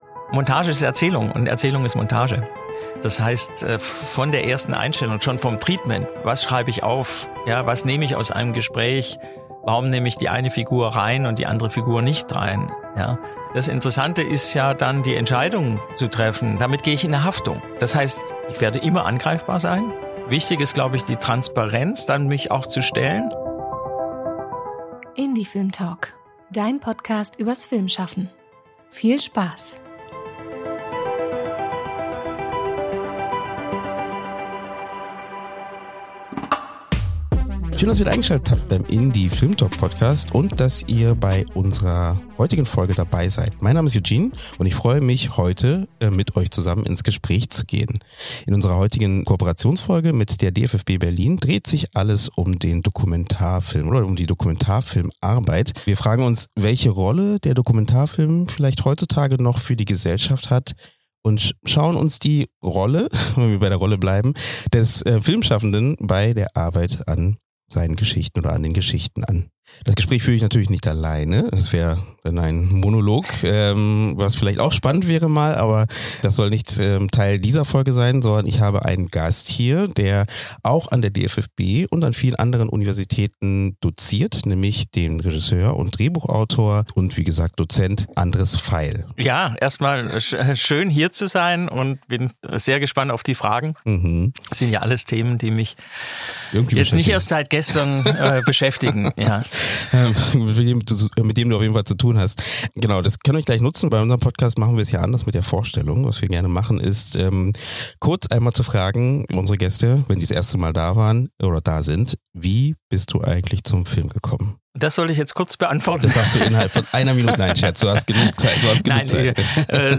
In unserem Gespräch mit Regisseur Andres Veiel sprechen wir über das genaue Hinsehen als Haltung, über Verantwortung im Umgang mit realen Protagonist*innen und über den Dokumentarfilm als „Rastplatz der Reflexion“ in einer überreizten Gegenwart. Es geht um Handwerk, um Beziehungsarbeit – und um die Frage, wie Filme Denkprozesse anstoßen können, statt schnelle Antworten zu liefern.